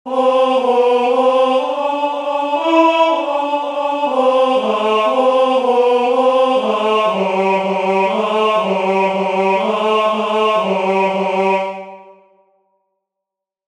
"Clamaverunt justi," the second antiphon from the first nocturn of Matins, Common of Apostles